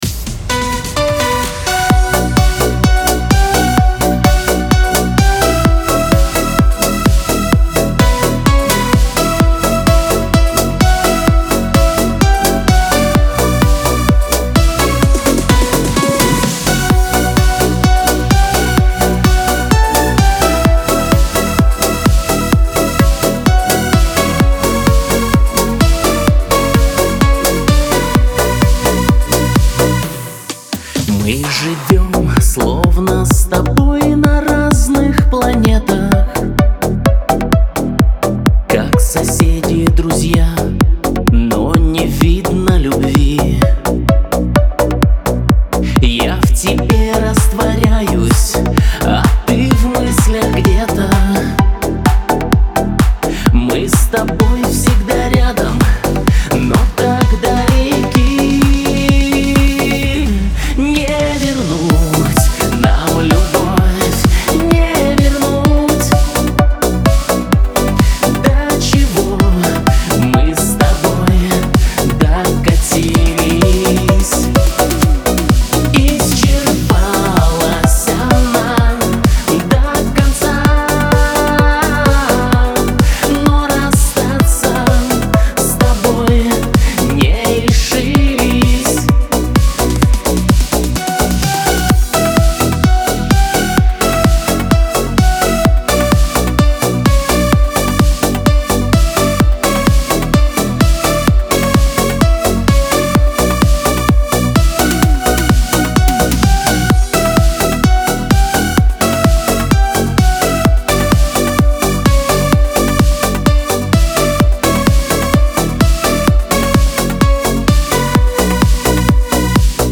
эстрада
pop